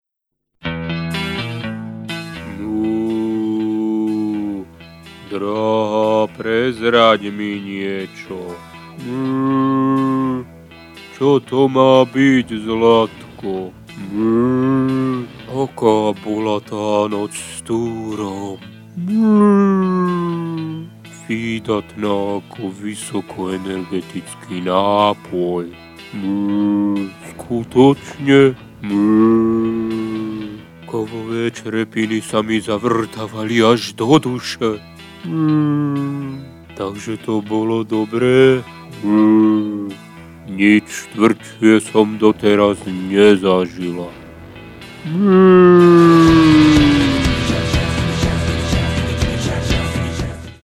ID jingel 4